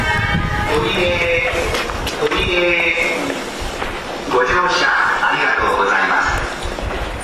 常磐線ホーム
駅名連呼